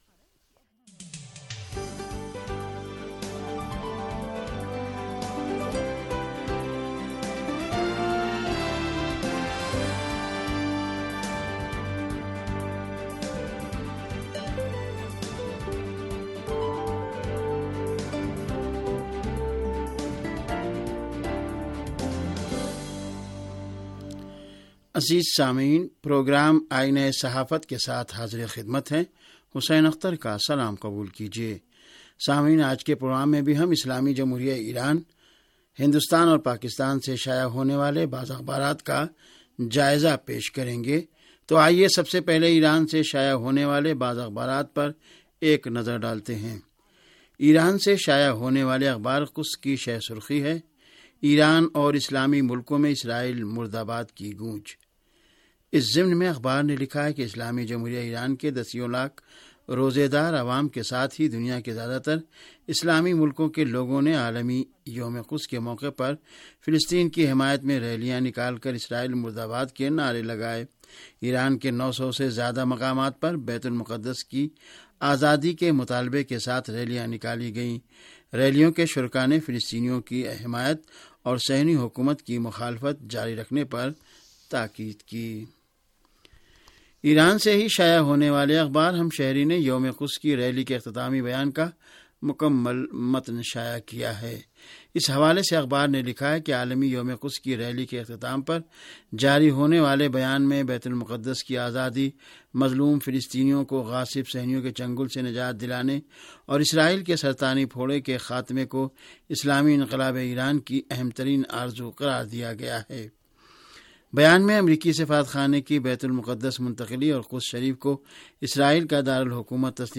ریڈیو تہران کا اخبارات کے جائزے پر مبنی پروگرام - آئینہ صحافت